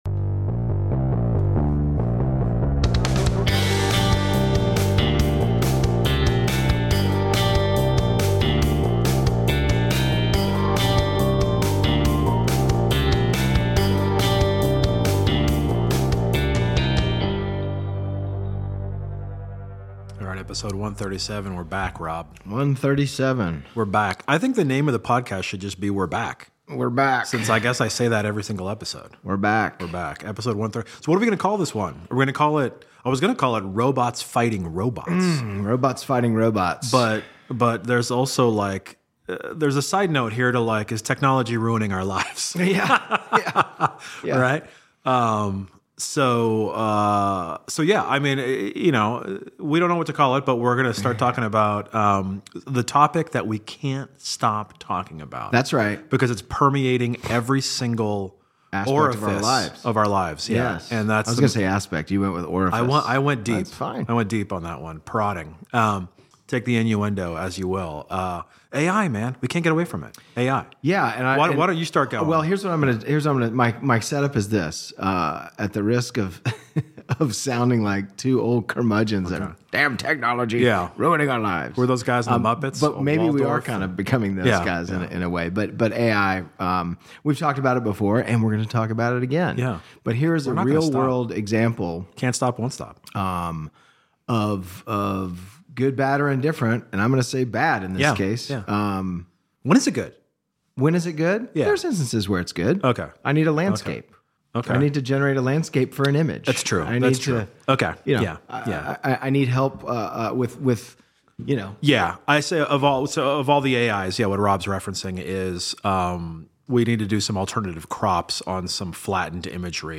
Join us as we unravel the complexities of AI's role in education, debate its benefits and pitfalls, and share real-world stories that highlight the ongoing battle between innovation and tradition. Tune in for a lively discussion filled with humor, insights, and a touch of futuristic wonder.